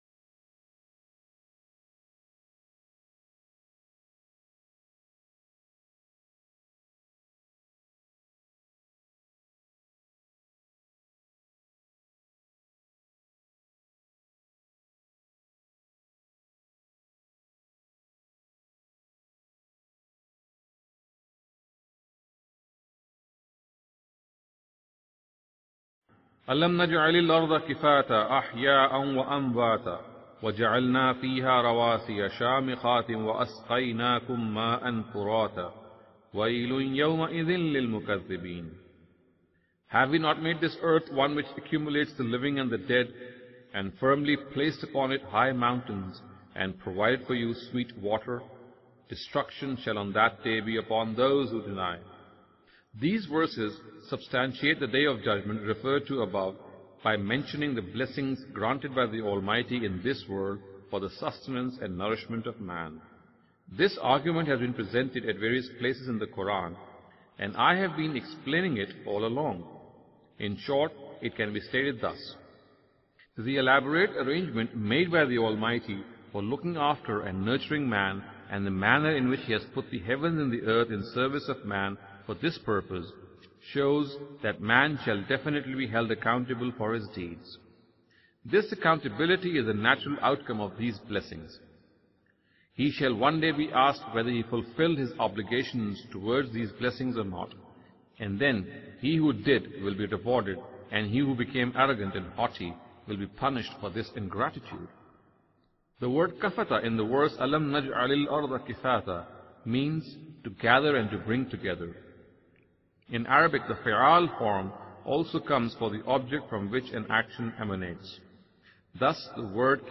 Imam Amin Ahsan Islahi's Dars-e-Qur'an.